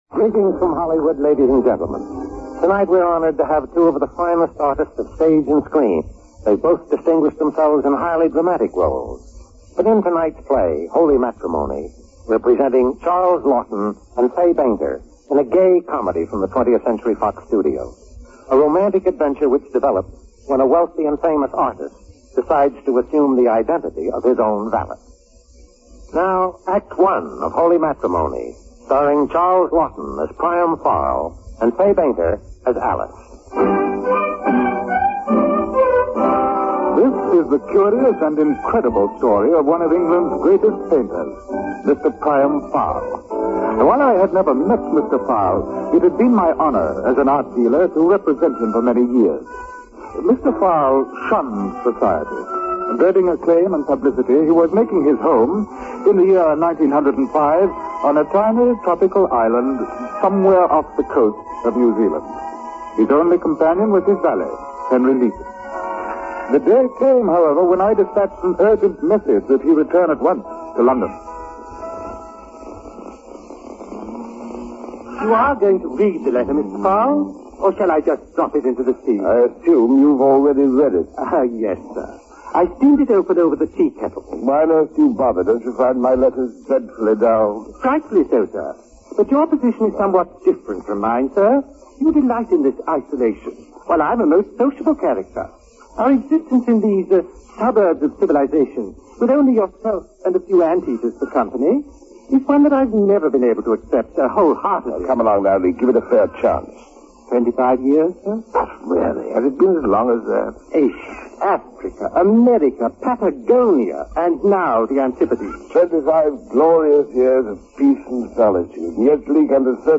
Holy Matrimony, starring Charles Laughton, Fay Bainter